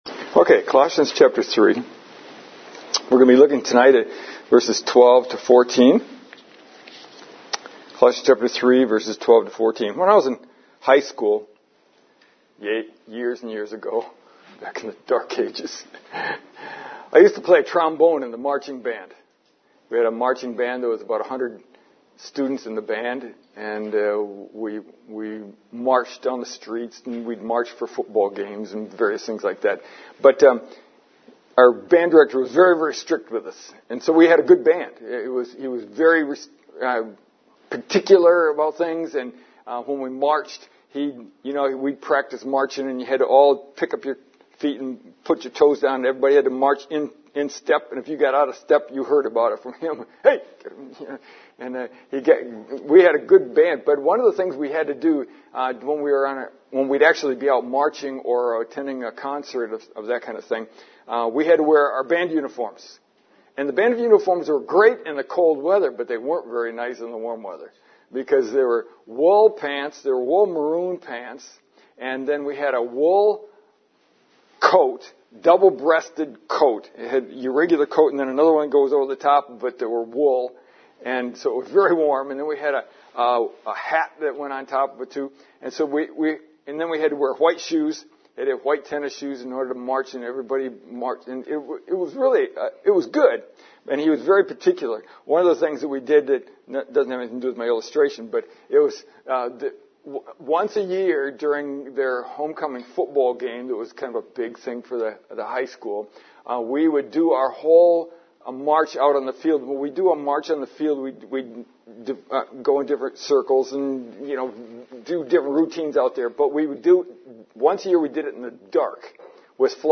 Paul gives us a similar analogy in our text this evening.